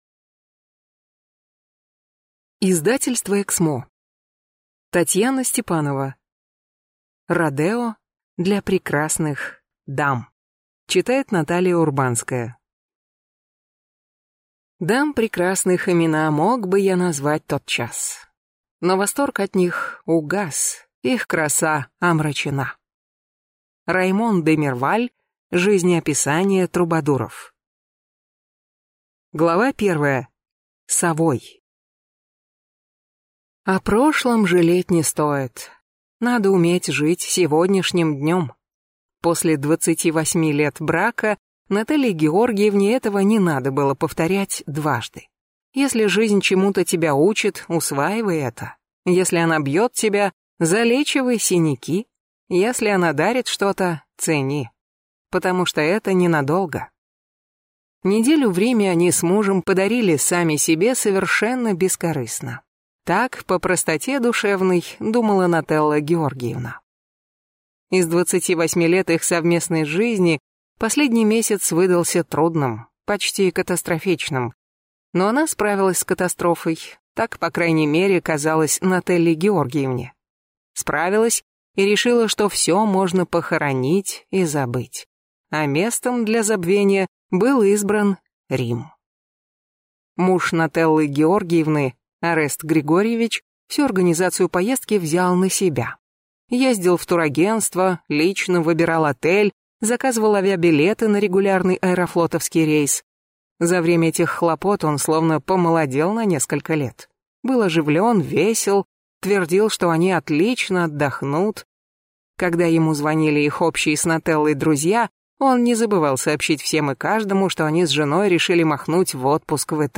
Аудиокнига Родео для прекрасных дам | Библиотека аудиокниг